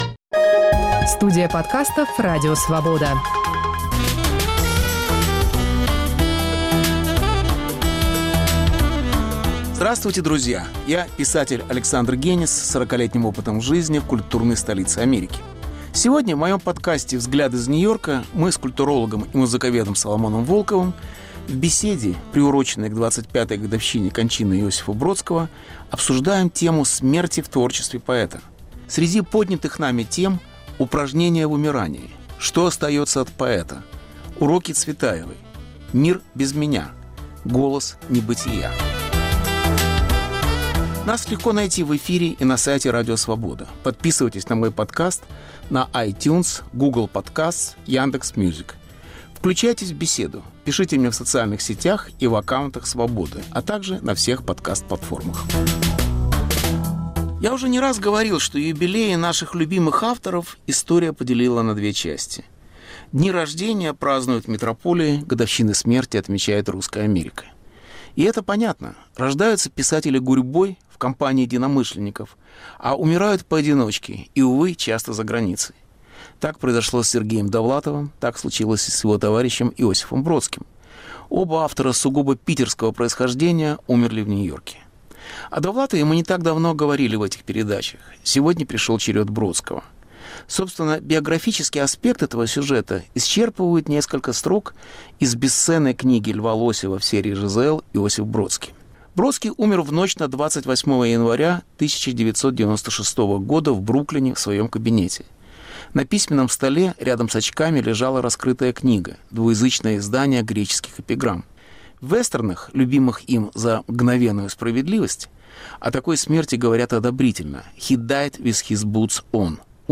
Беседа с Соломоном Волковым: к 25-летию кончины поэта.